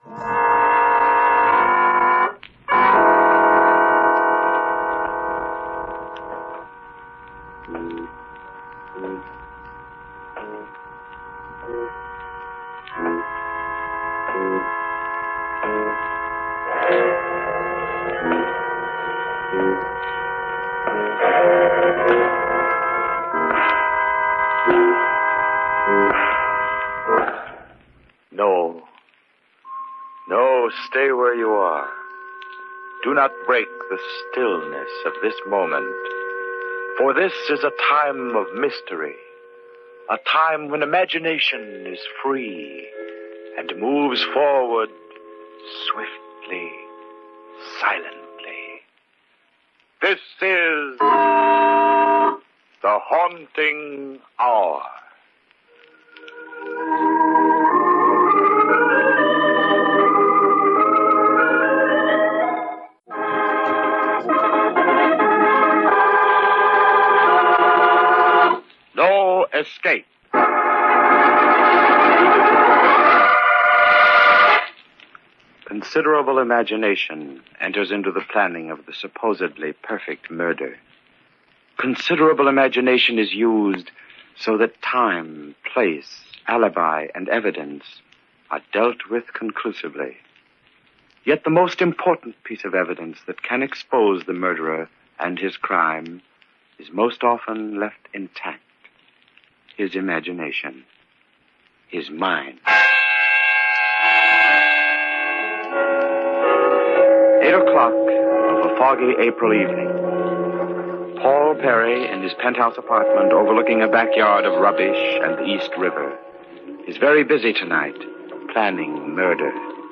On this episode of the Old Time Radiocast we present you with a double feature of the classic radio program The Haunting Hour!